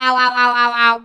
robo_death_01.wav